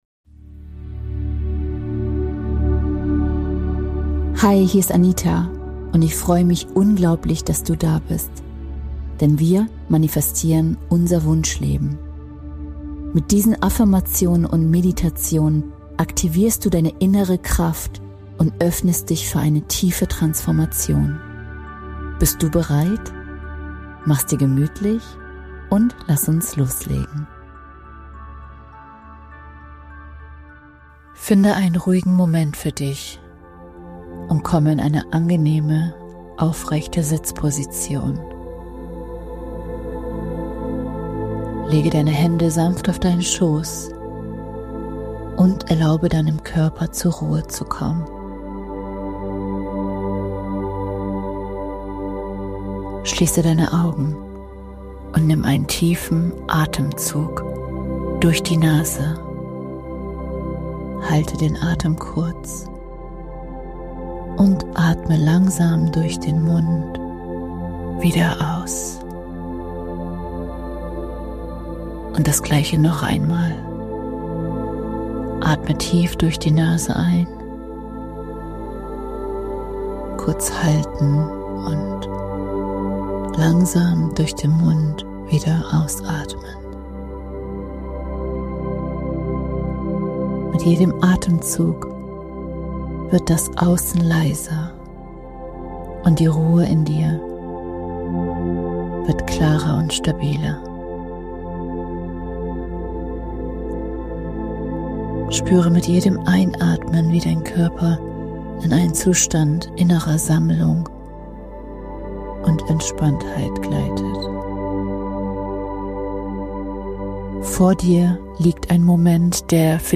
Diese geführte Meditation unterstützt dich dabei, Klarheit, Stärke und Vertrauen in dir zu aktivieren, bevor du...